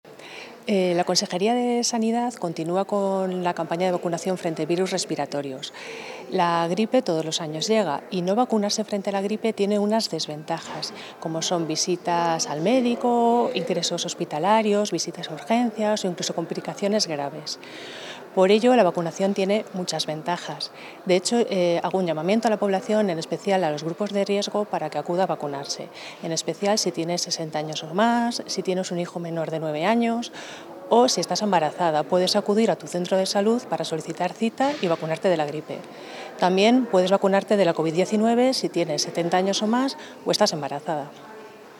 Intervención de la directora general de Salud Pública.